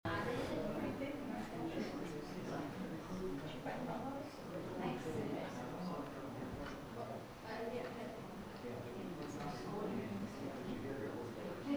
The sermon is from our live stream on 11/30/2025